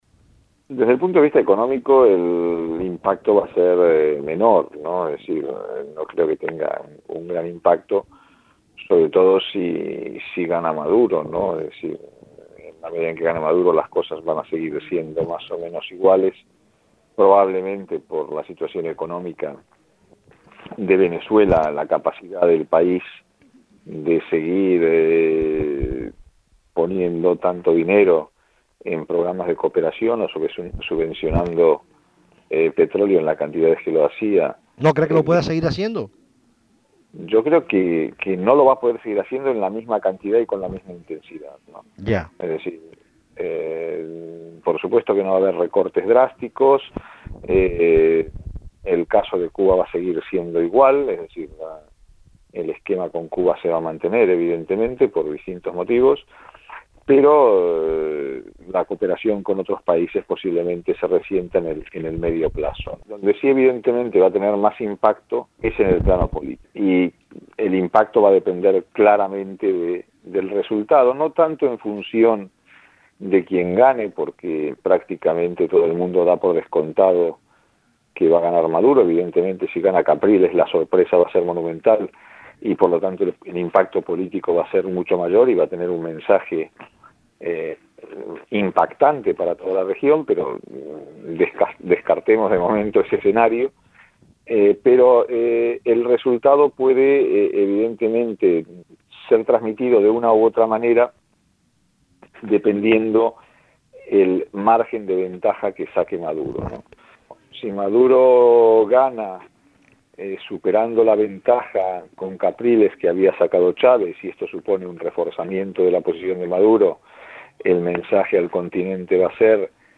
Entrevista apoyo latinoamericano